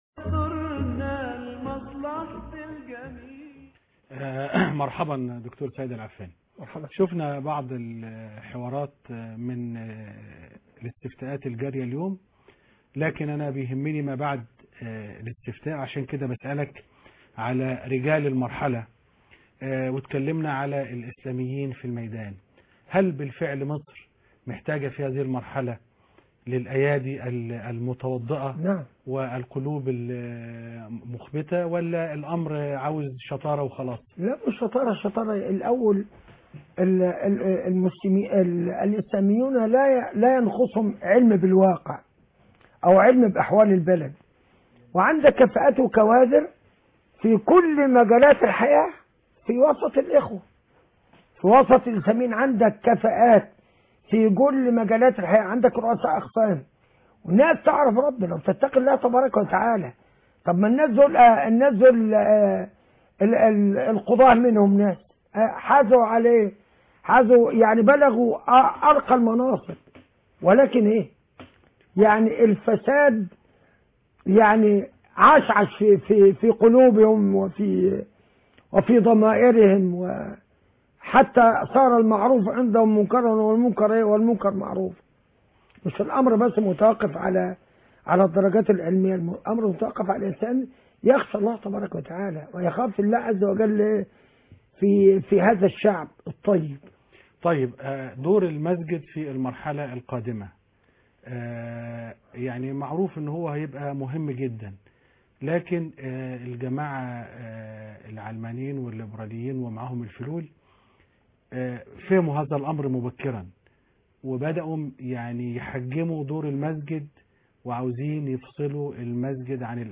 لقاء خاص